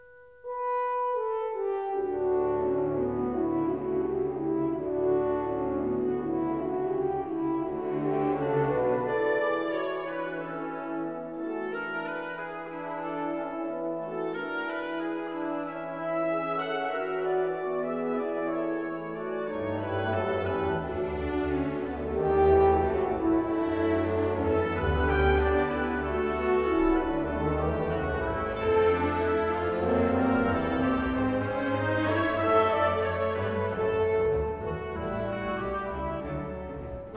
C sharp minor